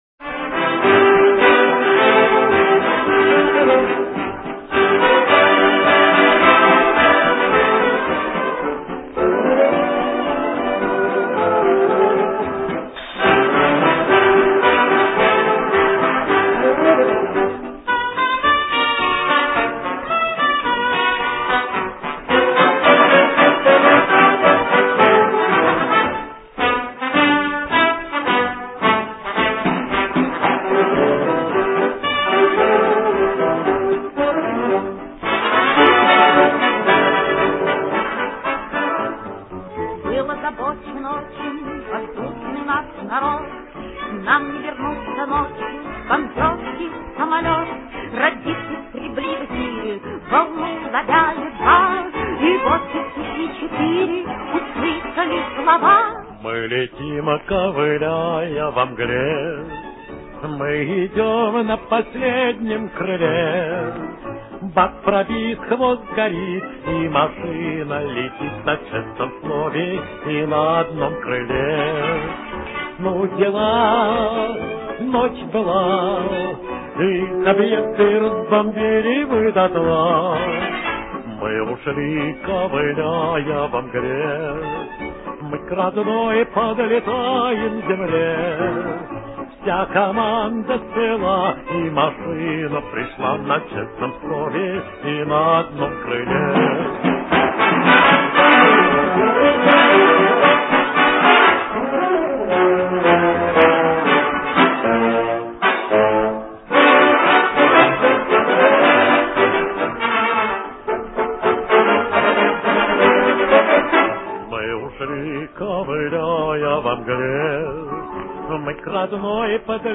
джазовую аранжировку